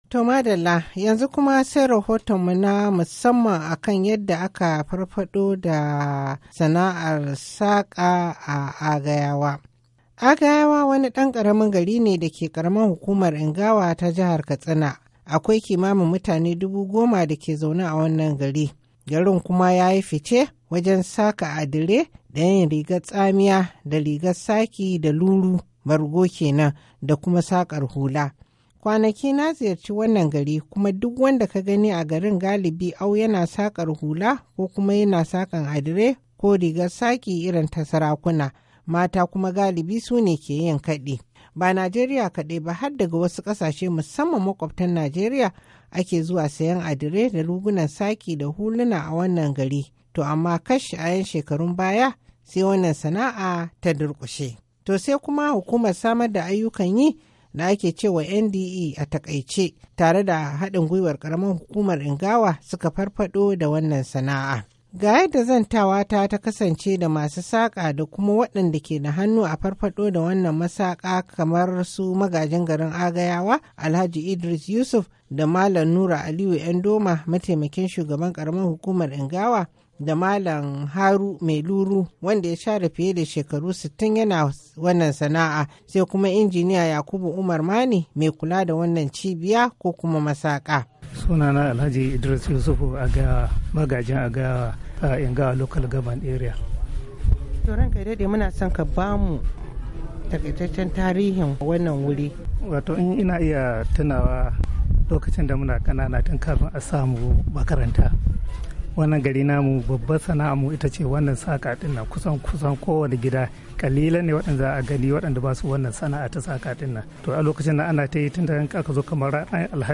Na zanta da wasu mutane da kuma jami'an da suke da hannu a farfado da masakar